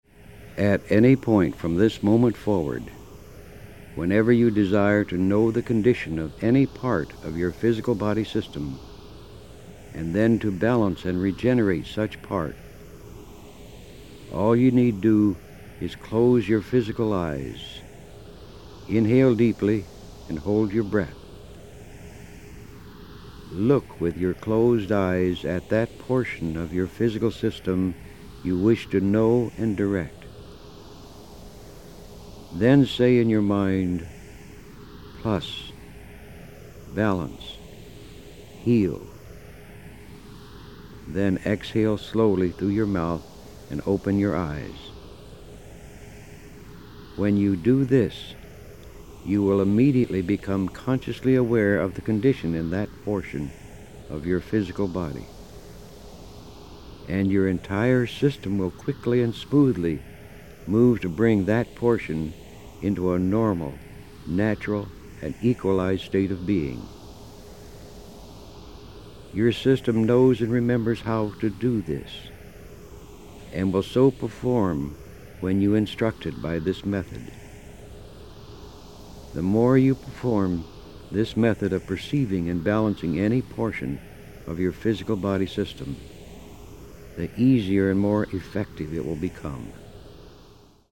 Verbální vedení: Anglické verbální vedení